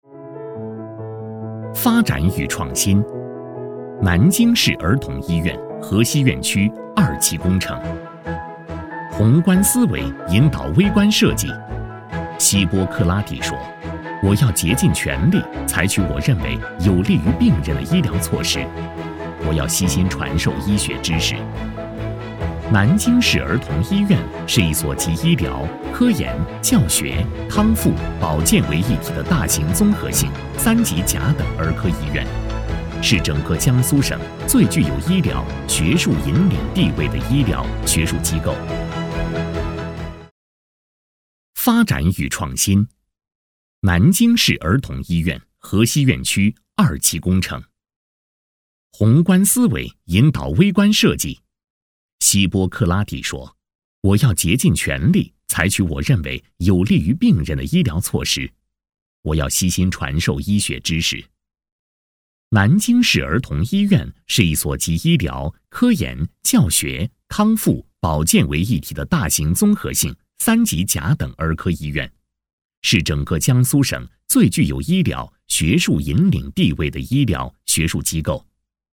配音风格： 磁性，年轻
【专题】南京市儿童医院